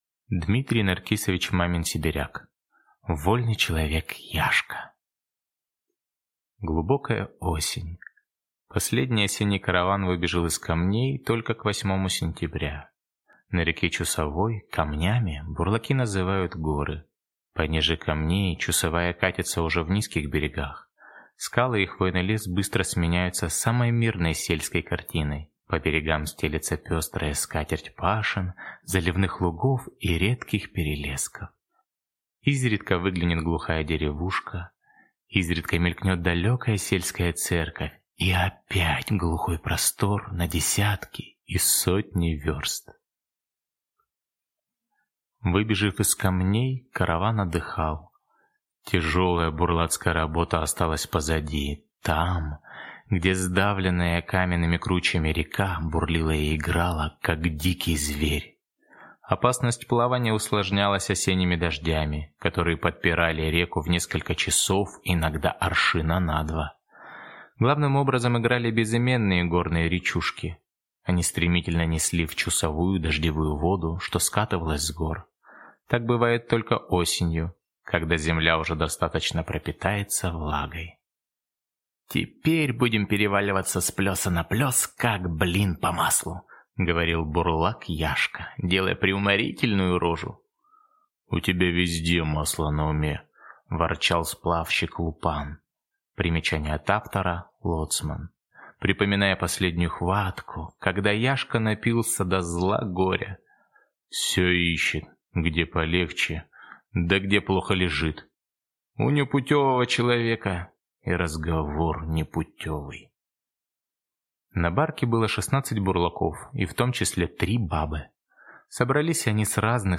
Аудиокнига Вольный человек Яшка | Библиотека аудиокниг